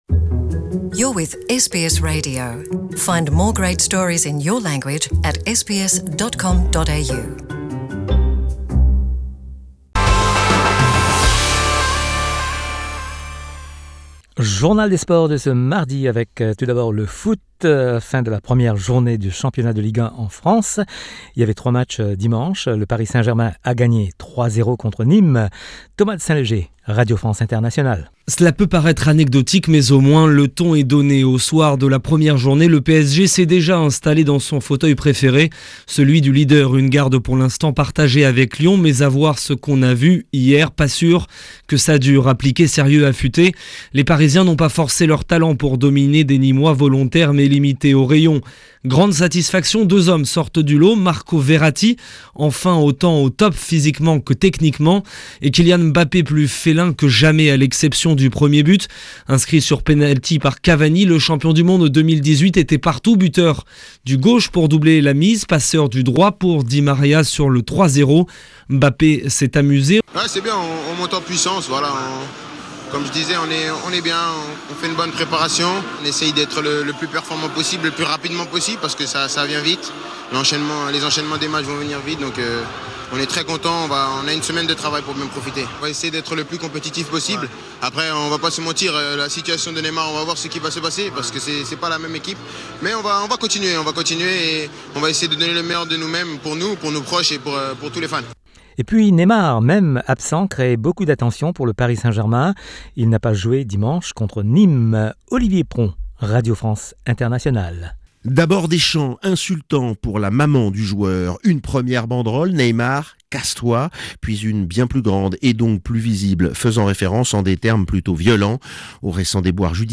Le journal des sports du 13 août
L’actualité sportive avec les sonores de RFI.